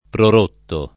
pror1mpo] — coniug. come rompere; assai rari però il part. pass. prorotto [
pror1tto] e i tempi composti — lett. ant. prorumpere [pror2mpere], coniug. similmente